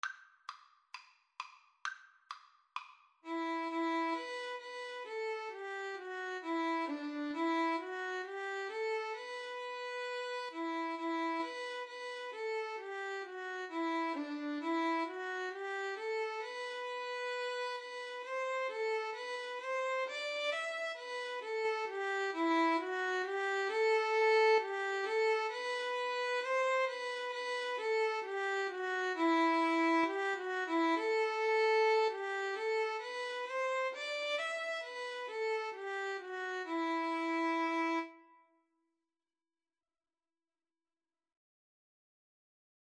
Play (or use space bar on your keyboard) Pause Music Playalong - Player 1 Accompaniment reset tempo print settings full screen
Slow two in a bar feel = c. 66
E minor (Sounding Pitch) (View more E minor Music for Violin-Cello Duet )